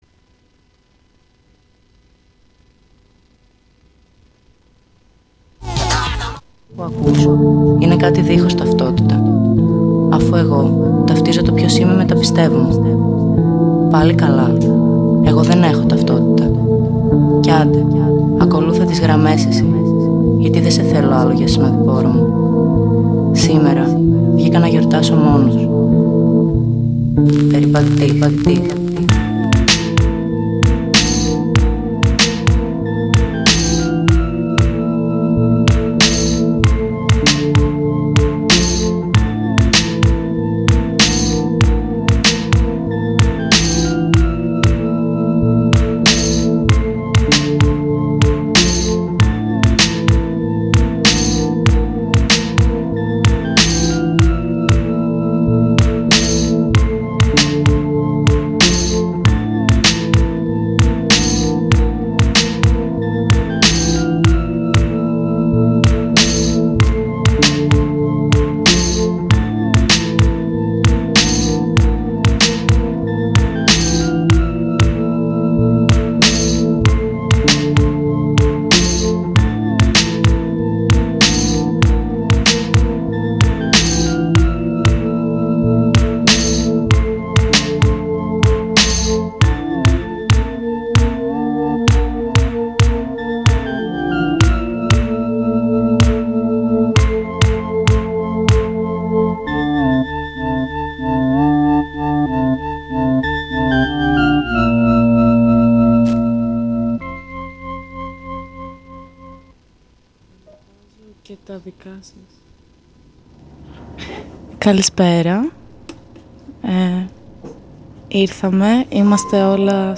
Θα τοποθετηθούν για την υπόθεσή τους οι δύο σύντροφοι, ανακαλώντας το πως διαδραματίστηκε η στοχοποιήση τους από τα κρατικά σκουπίδια κ.α., καθώς και θα αναλύσουμε την τότε συγκυρία και τους μηχανισμούς με τους οποίους το κράτος-κεφάλαιο επιτίθεται σε αντιεξουσιαστικά υποκείμενα.